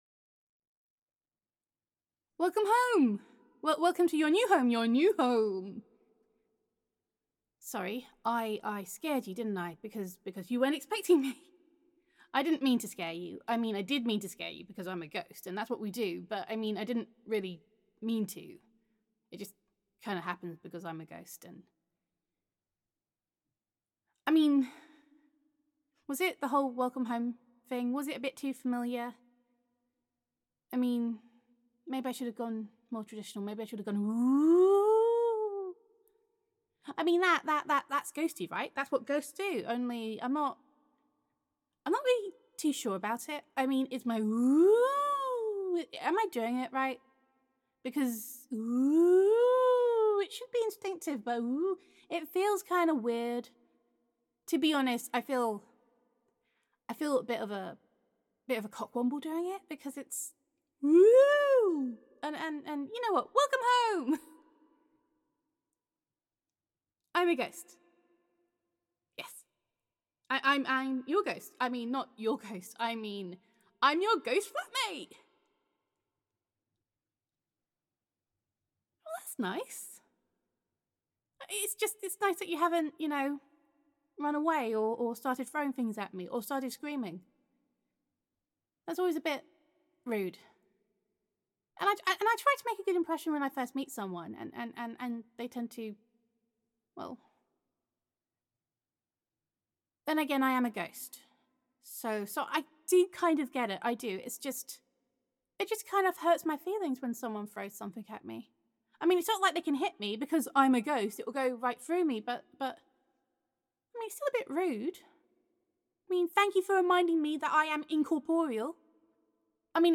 [F4A] Welcome Home
[an Extremely Awkward English Ghost]
[Happy Ghost Sounds]